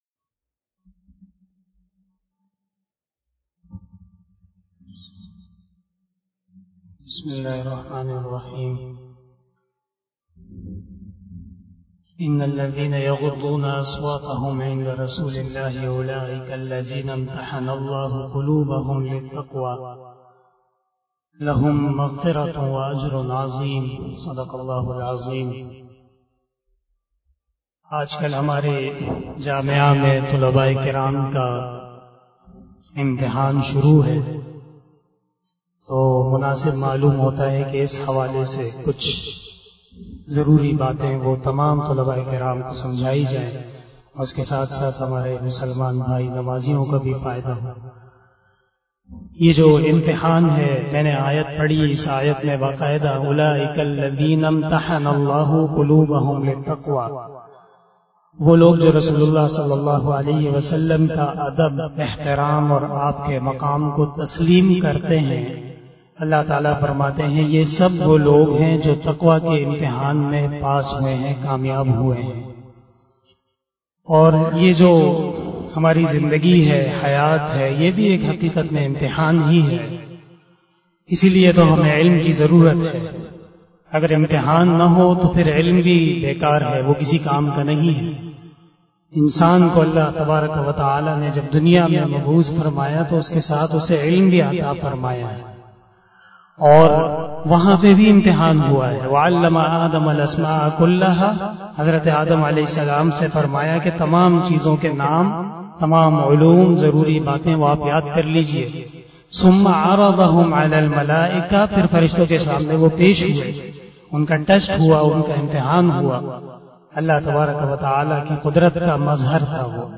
052 After Isha Namaz Bayan 05 September 2021 (27 Muharram 1443HJ) Friday